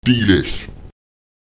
Lautsprecher delex [ÈdelE§] essen, trinken